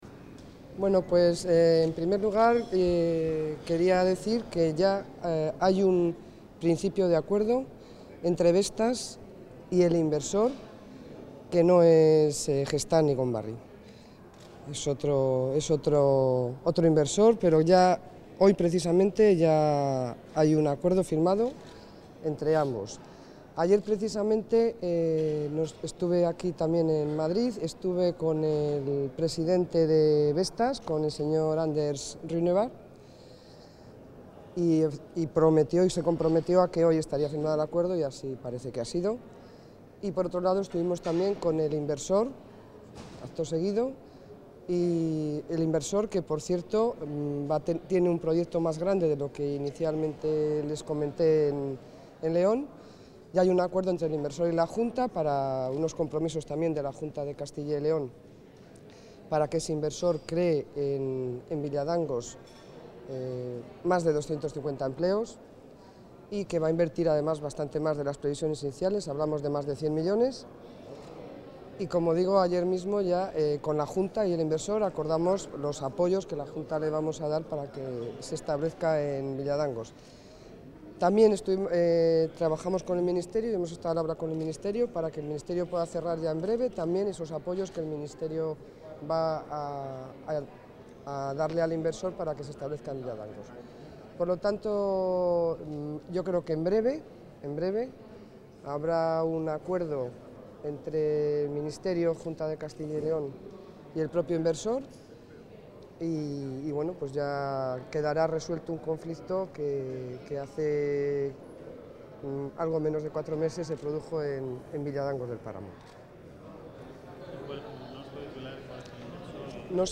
Declaraciones de la consejera de Economía y Hacienda sobre Vestas | Comunicación | Junta de Castilla y León
Declaraciones de la consejera de Economía y Hacienda sobre Vestas Contactar Escuchar 23 de noviembre de 2018 Castilla y León | Consejería de Economía y Hacienda Declaraciones de la consejera de Economía y Hacienda, Pilar del Olmo, sobre el acuerdo alcanzado entre la Junta, un inversor de Castilla y León y Vestas respecto a la fábrica de esta compañía en Villadangos del Páramo, en León.